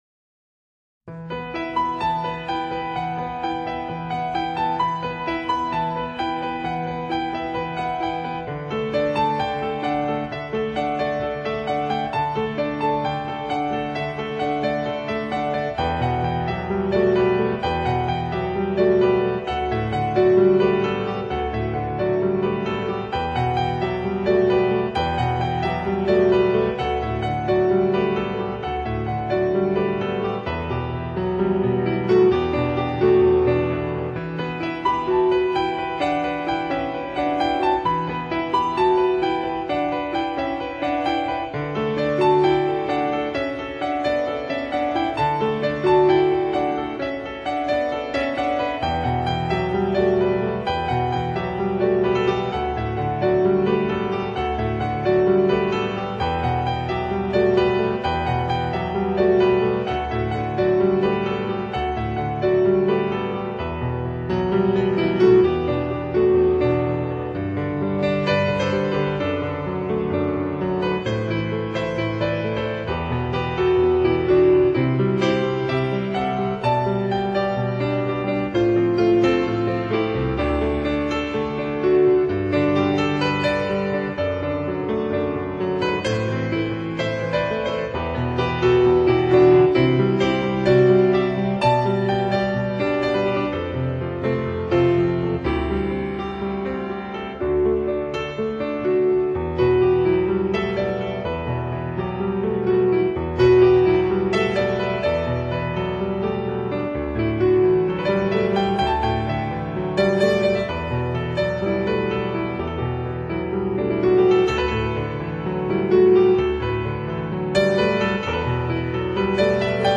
名家钢琴